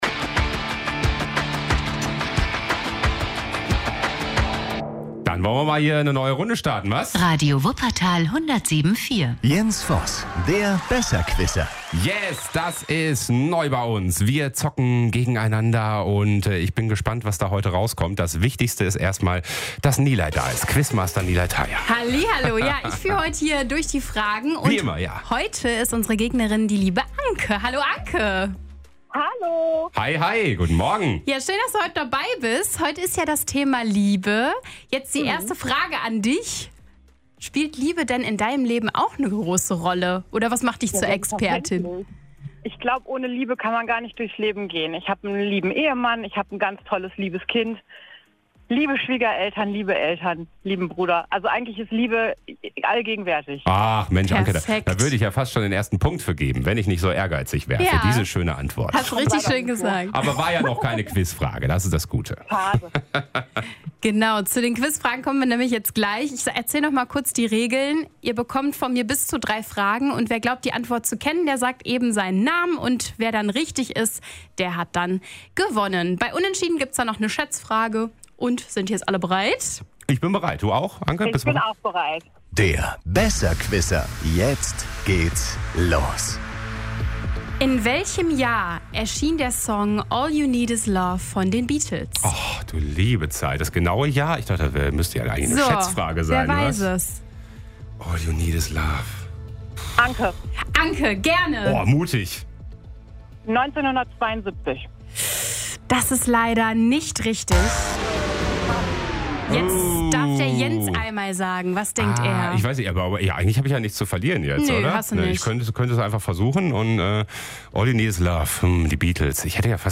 Wer die Antwort weiß, ruft schnell seinen Namen. Wer zuerst richtig antwortet, holt den Punkt.